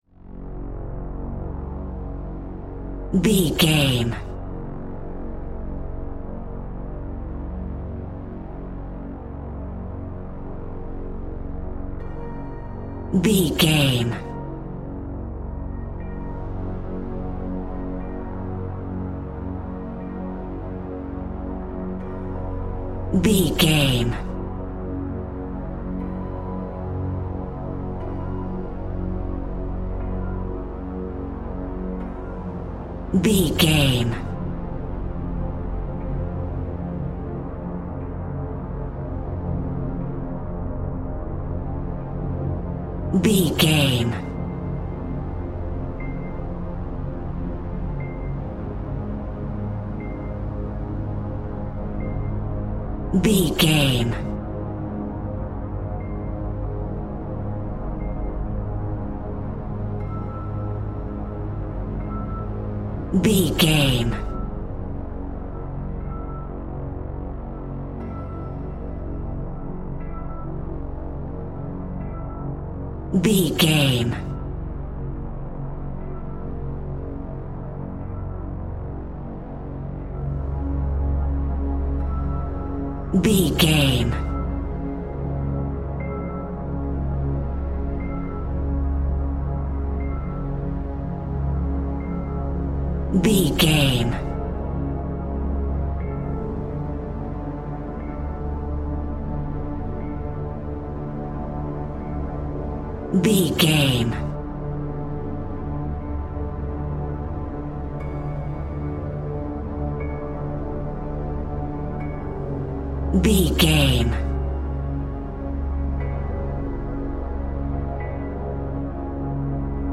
Electro Horror Atmosphere Music Cue.
Atonal
F#
scary
ominous
dark
haunting
eerie
synthesizer
piano
drums
Horror Pads